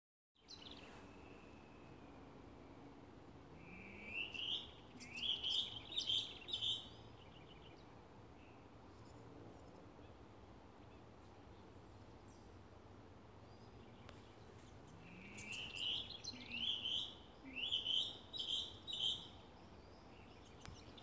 Plumbeous Rail (Pardirallus sanguinolentus)
070919-AVE-Gallineta-comun.mp3
Life Stage: Adult
Detailed location: Eco Área Avellaneda
Condition: Wild
Certainty: Recorded vocal